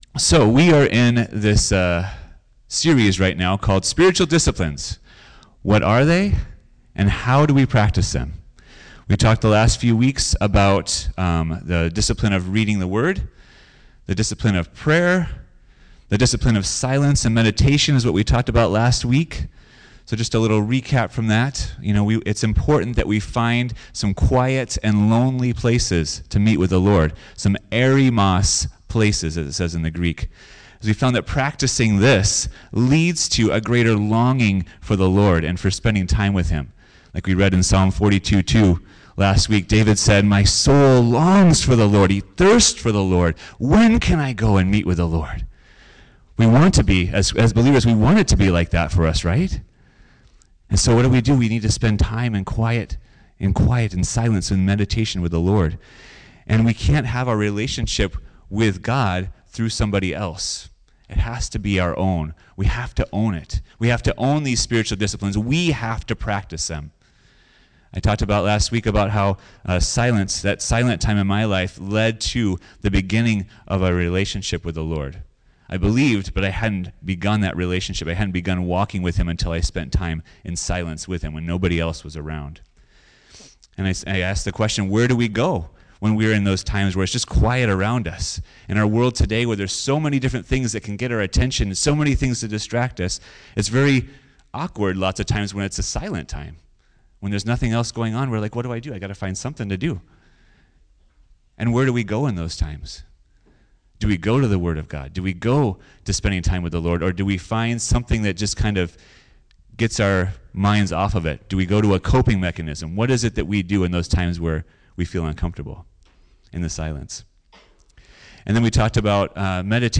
Generosity Preacher